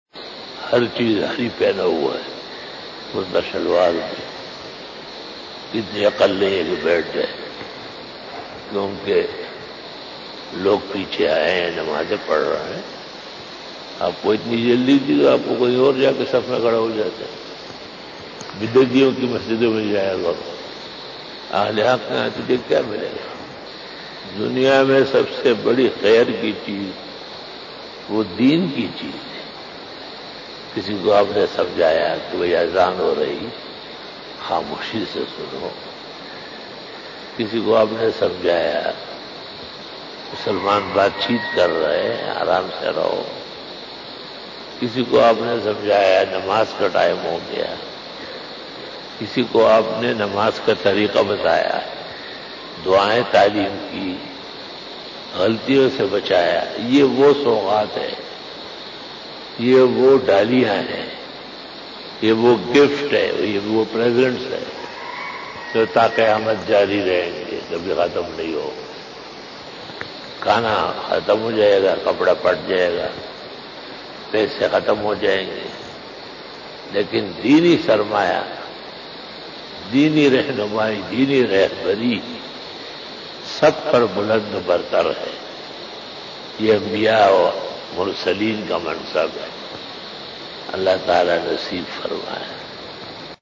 Asar bayan 17 September 2020 (28 Muharram 1442HJ) Thursday